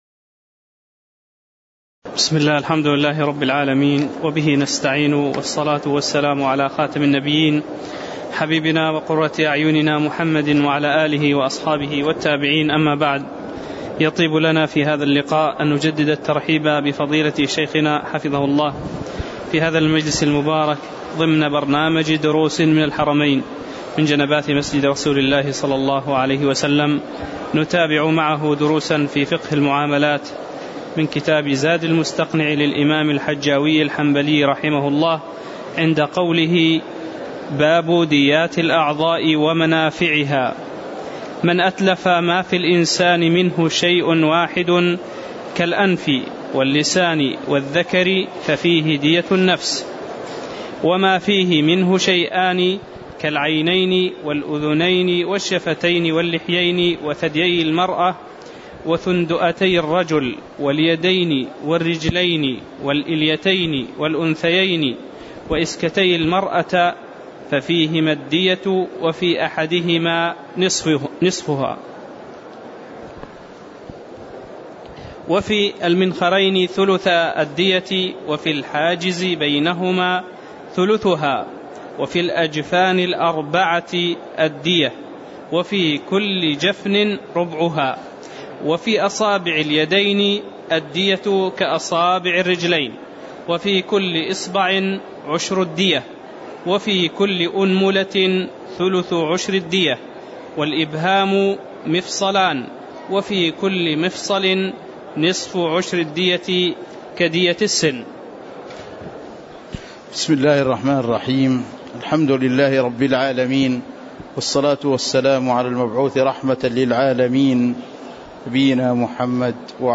تاريخ النشر ١٩ ربيع الأول ١٤٣٨ هـ المكان: المسجد النبوي الشيخ